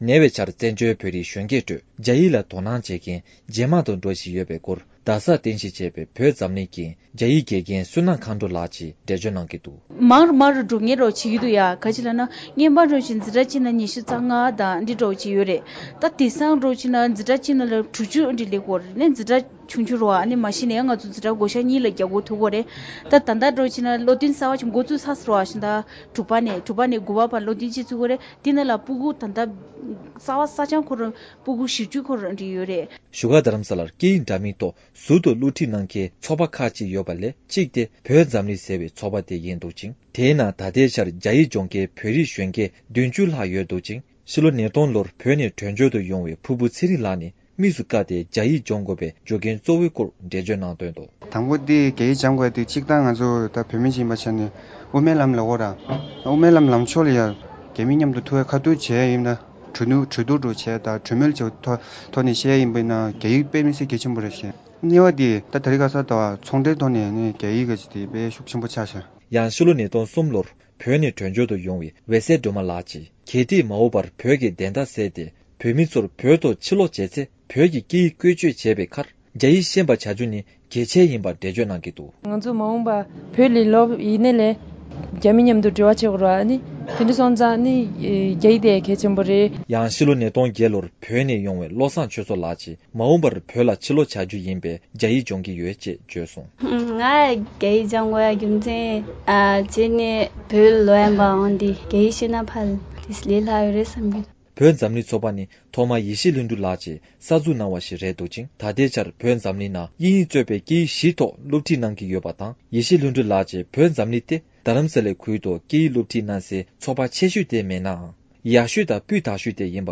གནས་ཚུལ